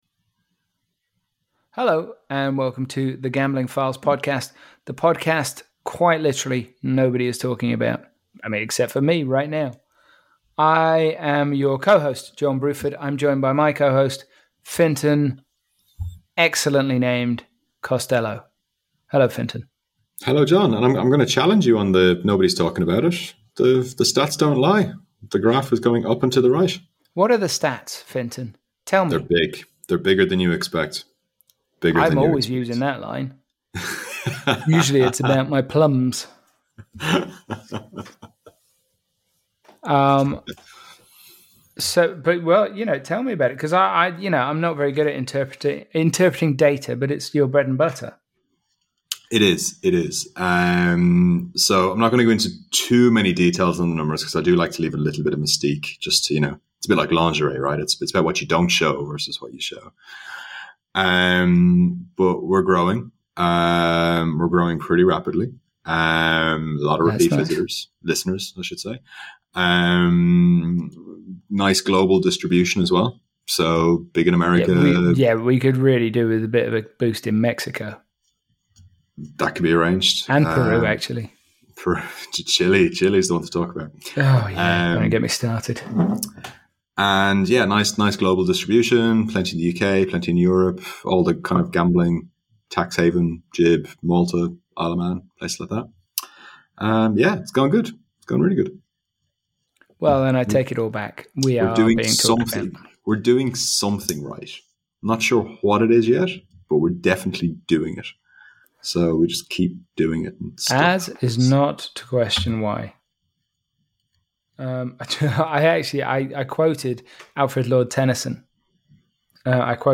Just two guests this week but we're pretty sure the show is a winner.